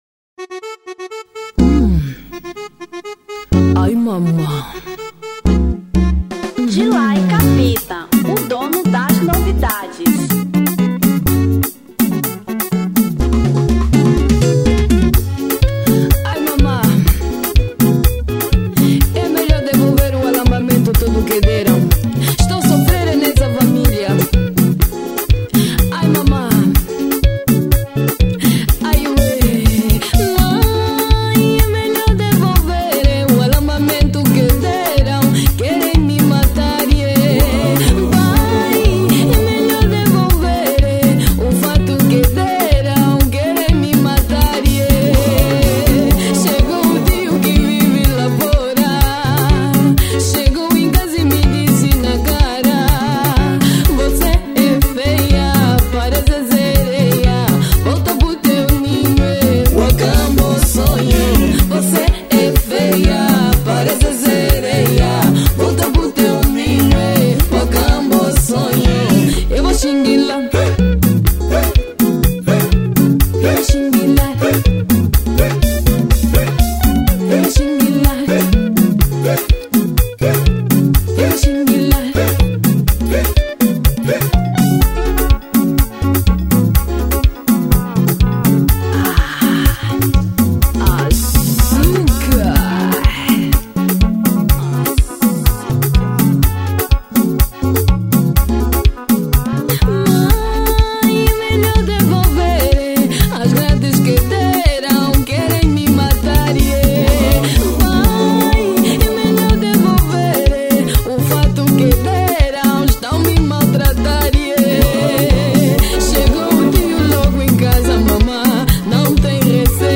Kizomba 2010